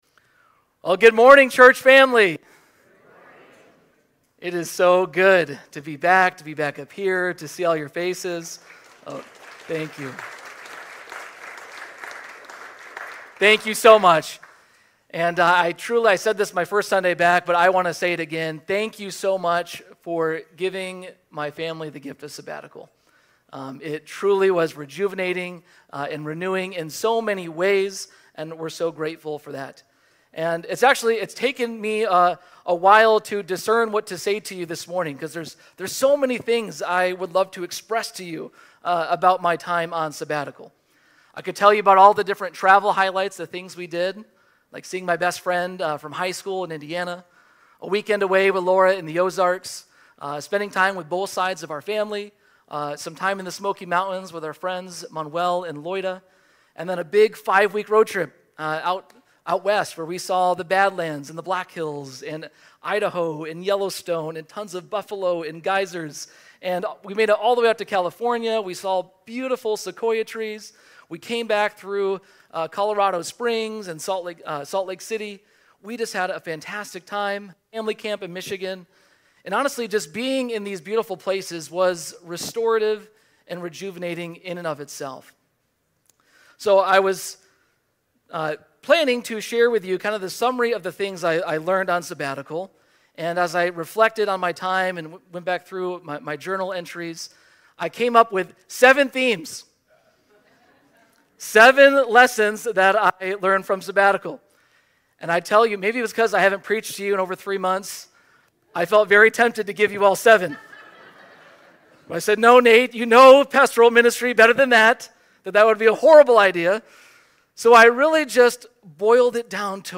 Sermons | Faith Covenant Church